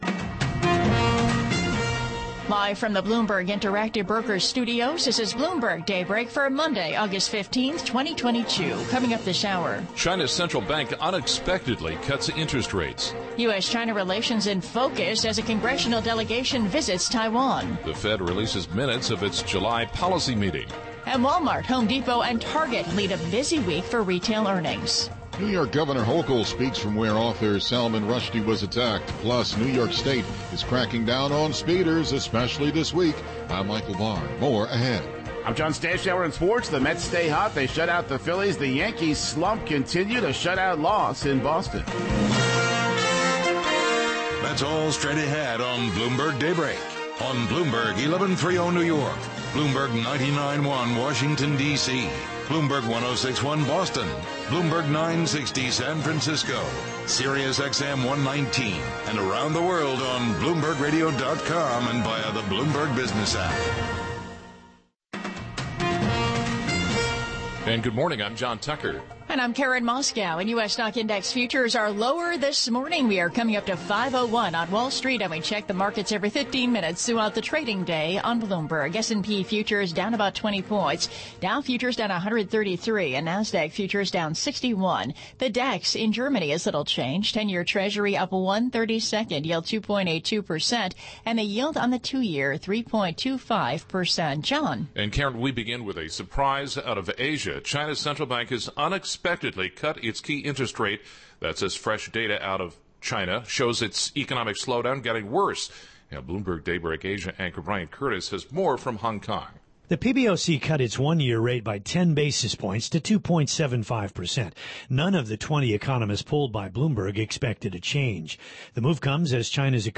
Bloomberg Daybreak: August 15, 2022 - Hour 1 (Radio)